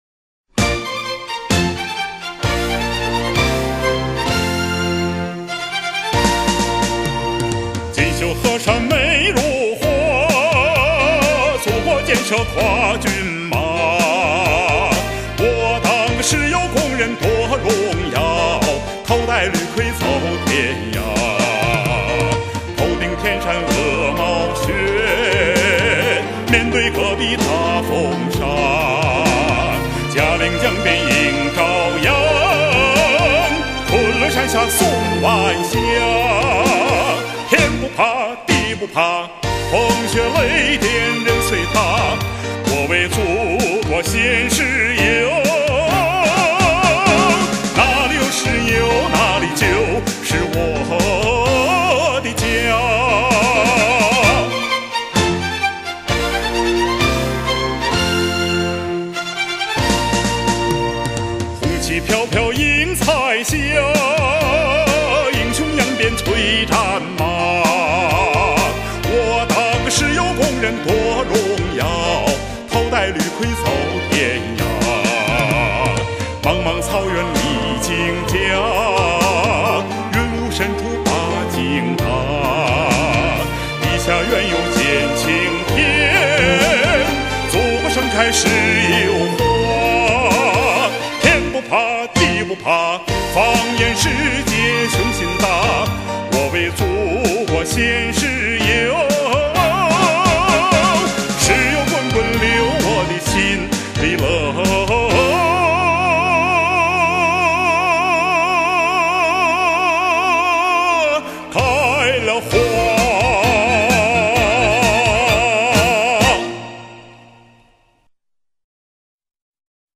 音乐风格: 流行